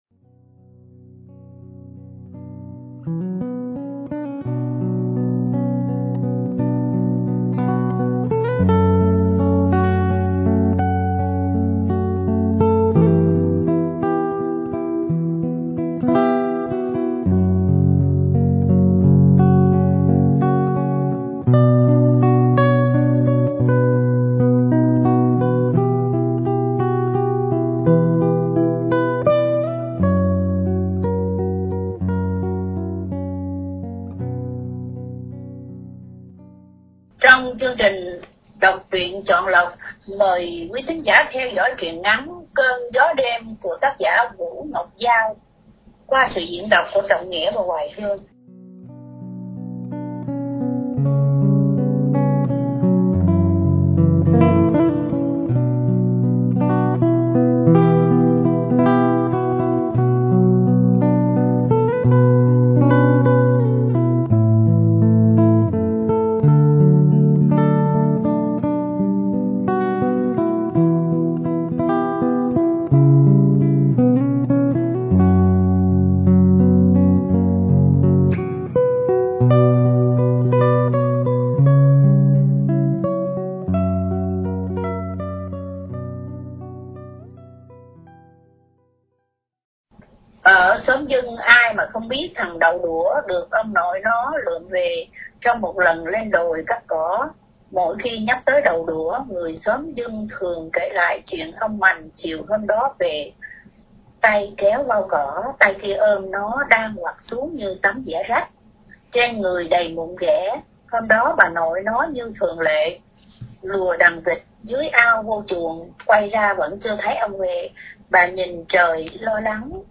Đọc Truyện Chọn Lọc